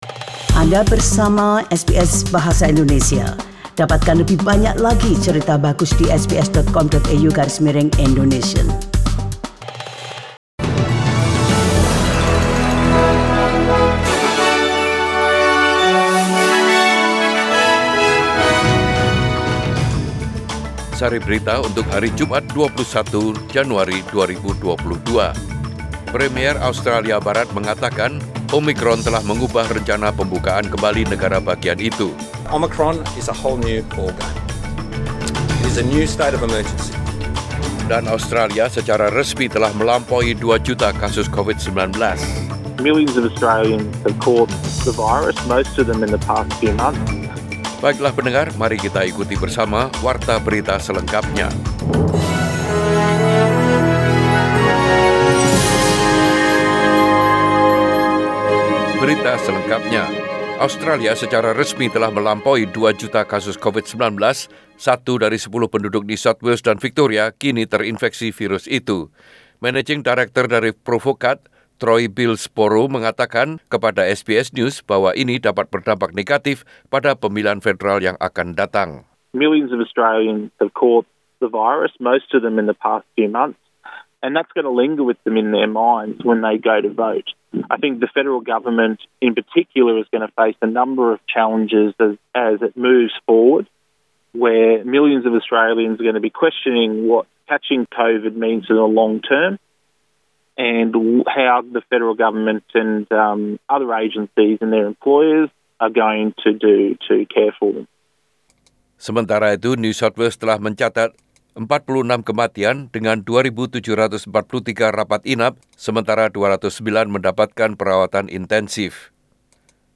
SBS Radio News in Bahasa Indonesia - 21 January 2022